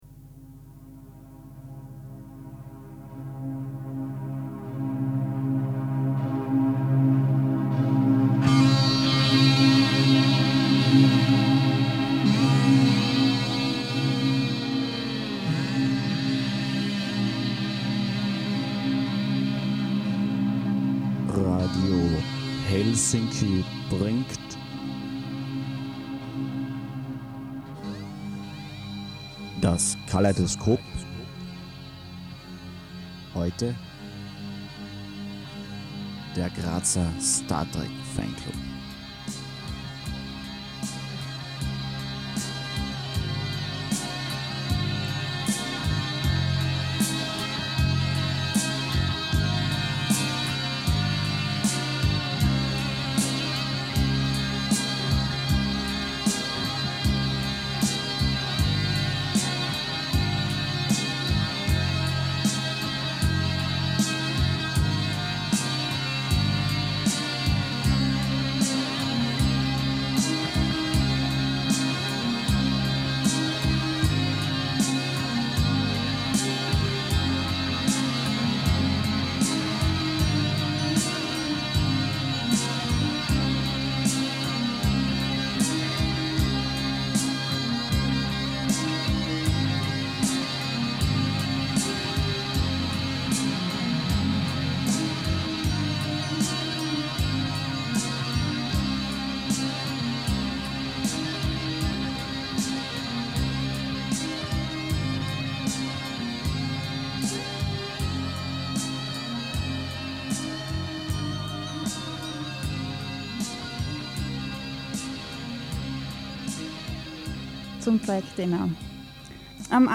Nostalgie: Radio Helsinki-Bericht über das trekdinner (Oktober 1996)
trekdinnergraz_auf_radiohelsinki.mp3